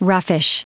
Prononciation du mot roughish en anglais (fichier audio)
Prononciation du mot : roughish